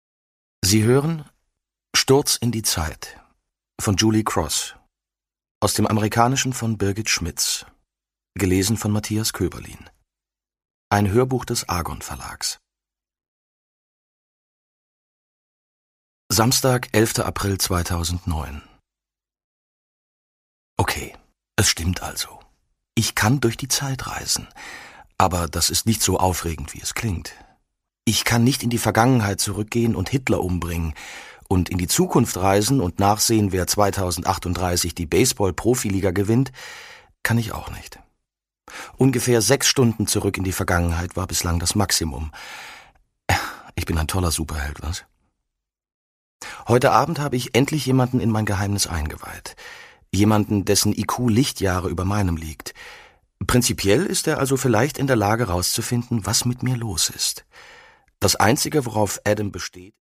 Fassung: Ungekürzte Lesung
Gelesen von: Matthias Koeberlin